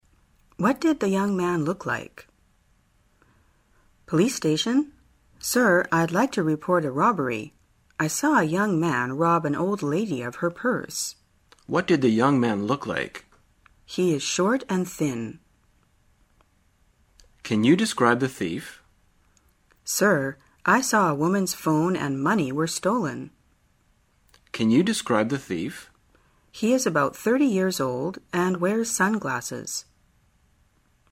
旅游口语情景对话 第336天:如何让其描述坏人